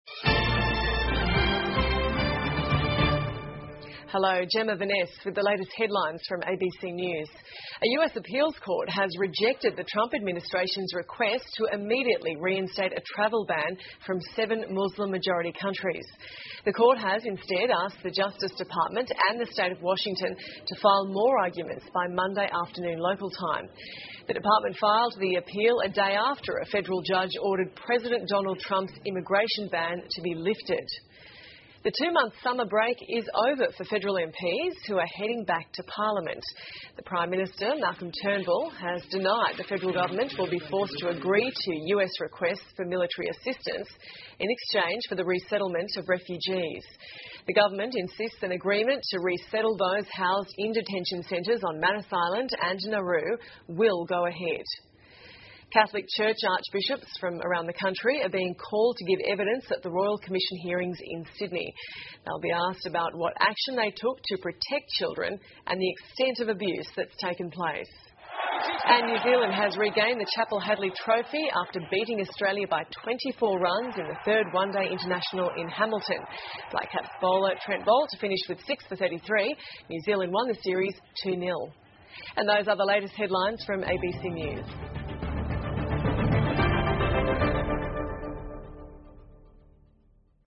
澳洲新闻 (ABC新闻快递) 美国法官下令暂停驱逐穆斯林 澳称难民安置协议将继续实施 听力文件下载—在线英语听力室